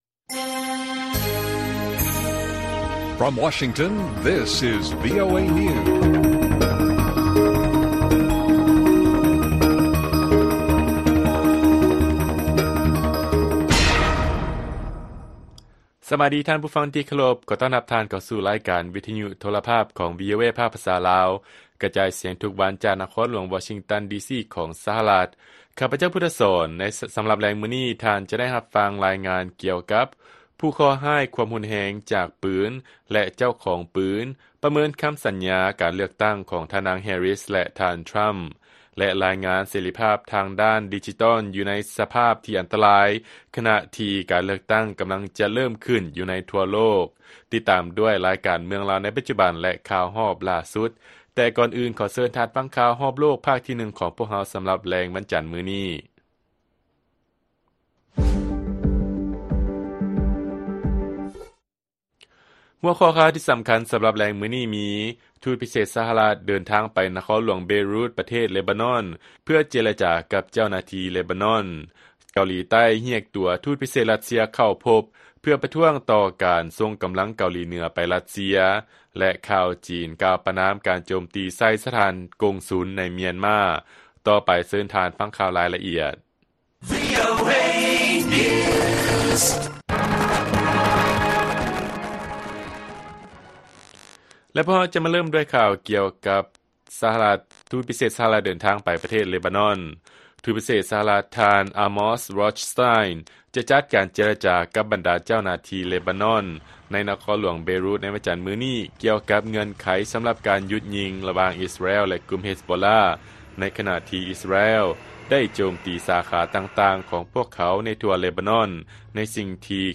ລາຍການກະຈາຍສຽງຂອງວີໂອເອລາວ: ທູດພິເສດ ສະຫະລັດ ເດີນທາງໄປນະຄອນຫຼວງ ເບຣຸດ ເພື່ອເຈລະຈາກັບ ເຈົ້າໜ້າທີ່ ເລບານອນ